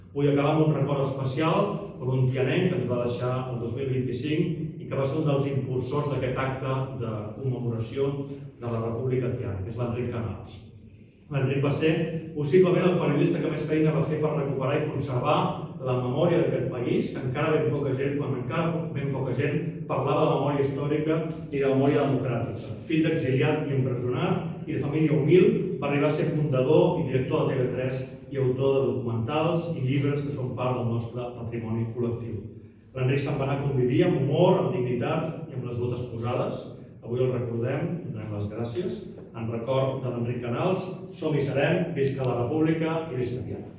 La Sala de Plens ha acollit aquest dimarts 14 d’abril lacte d’homenatge als tres alcaldes republicans que van haver de marxar a l’exili, Eduard Simó, Marcel·lí Garriga i Josep Rosselló.